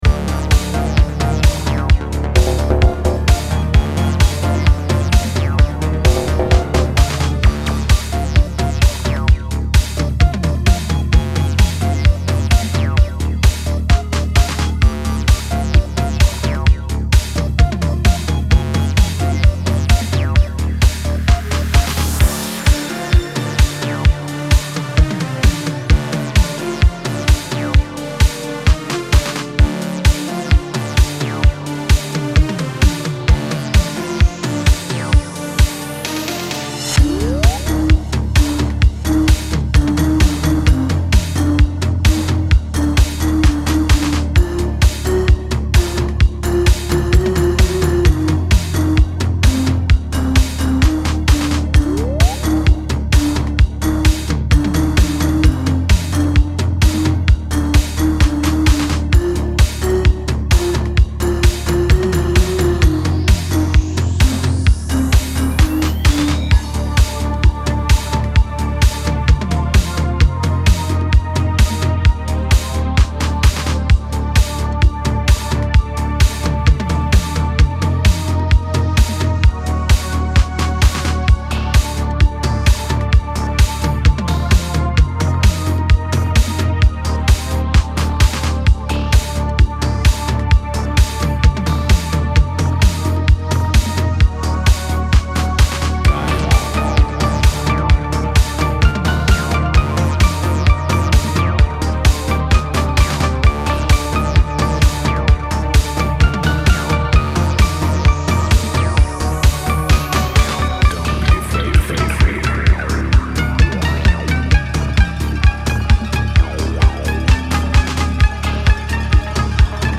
dark flavour oozing throughout the EP